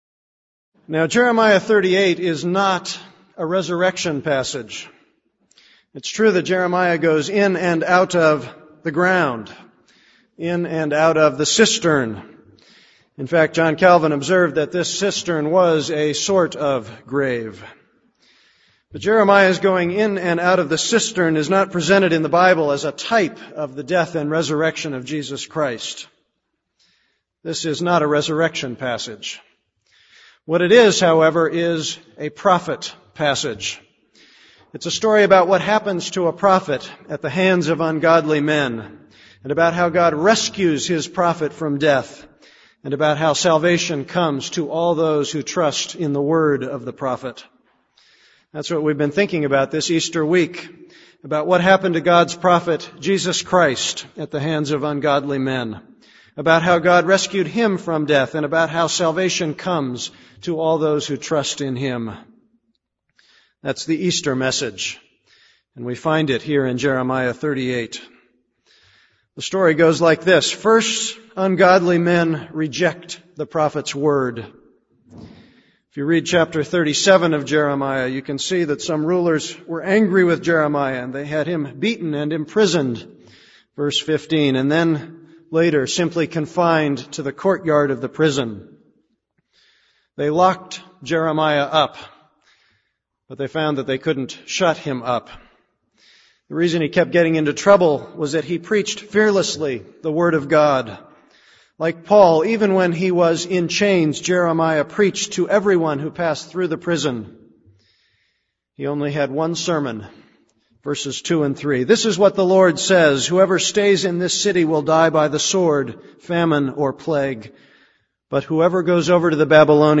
This is a sermon on Jeremiah 38:1-13.